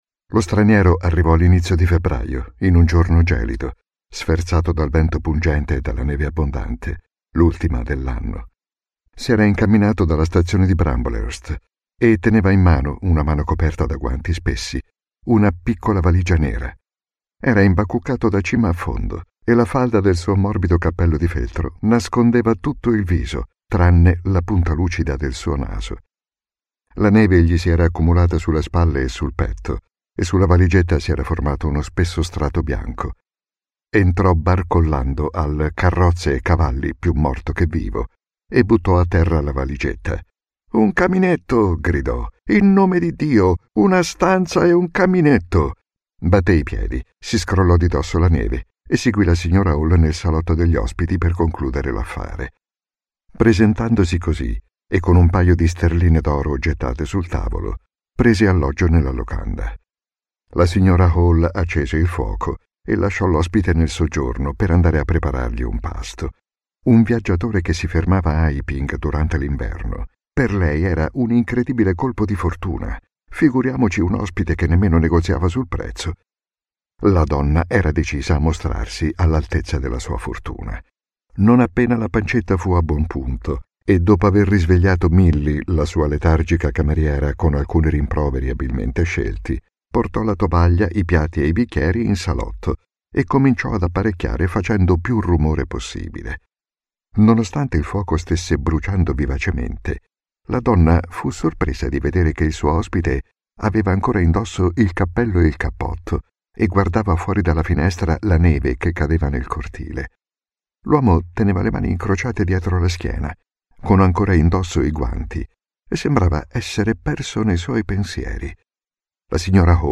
L'uomo invisibile audiolibro